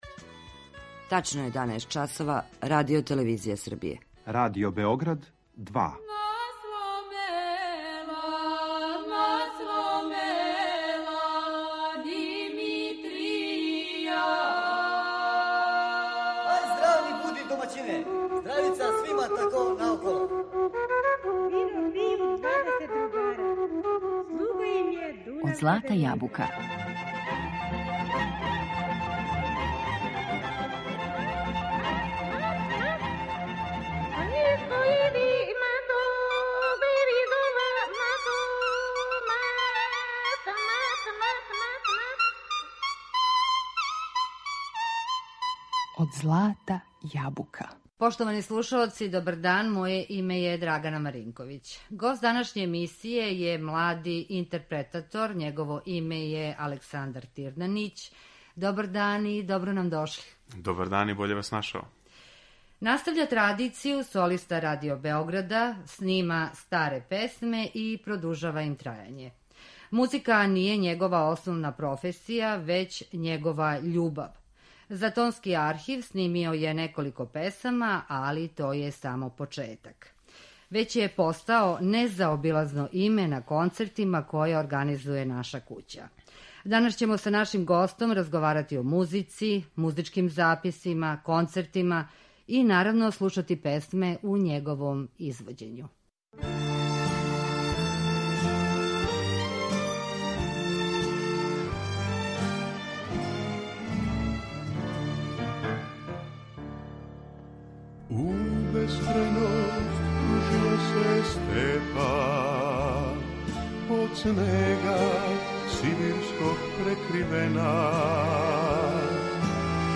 Са данашњим гостом разговараћемо о музици, записима, концертима и слушаћемо песме које он изводи.